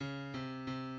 key Bm
transposed -5 from original Em